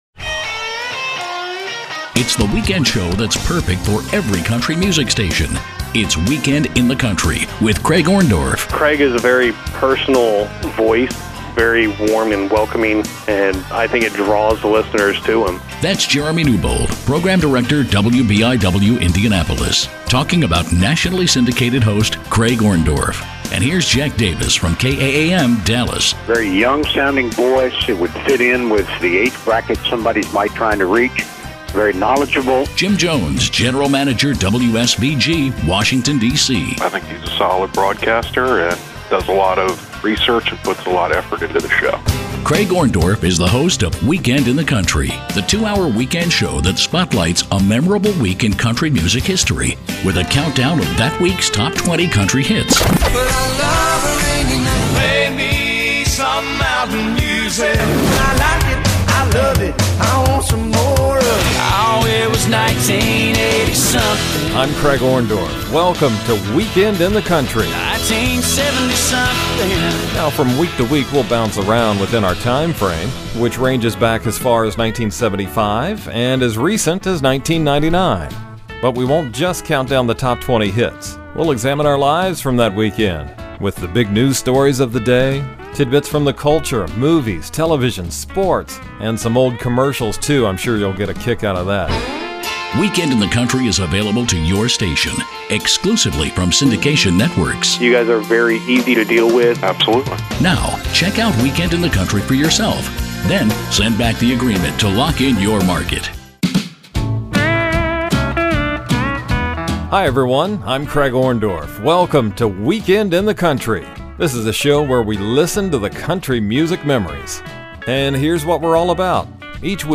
In a fast-paced two-hour voyage through time, each episode spotlights one memorable week in Country Music between 1980 and 1999.